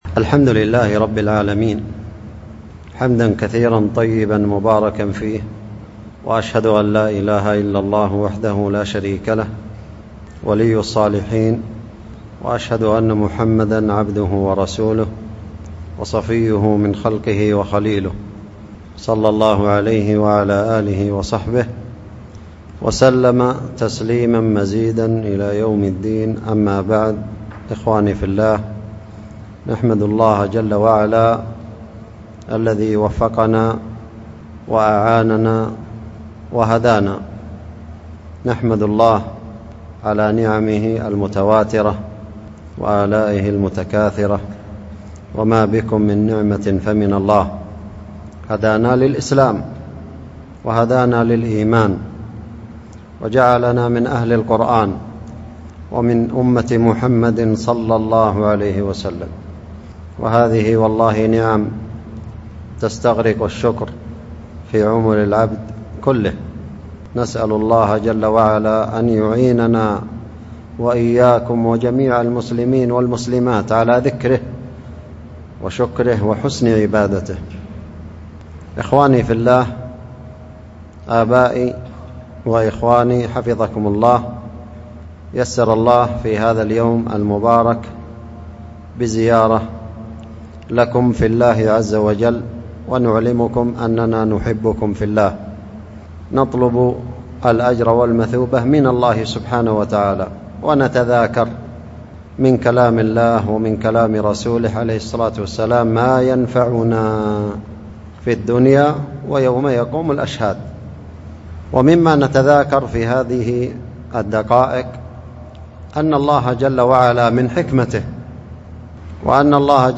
كلمة بعد صلاة العصر، عن فضل آخر النهار، وأهمية استغلالة ، نفع الله بها